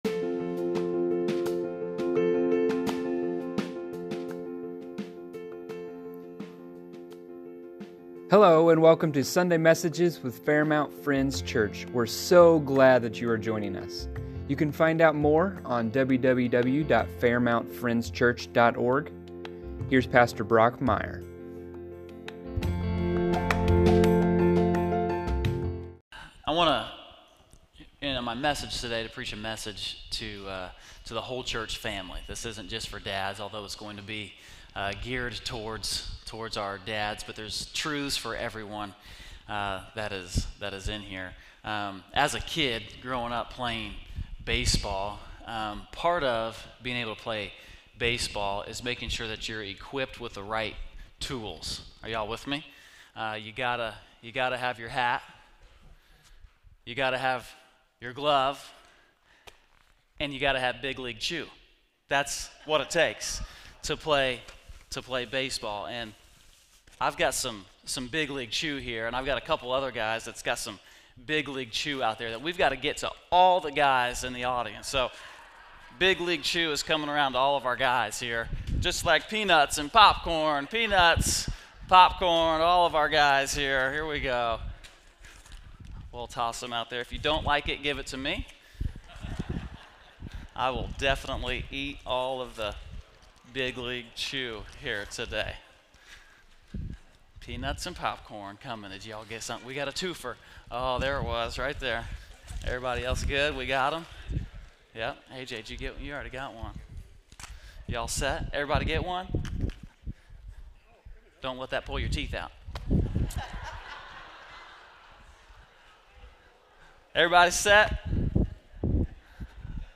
Sunday Messages | Fairmount Friends Church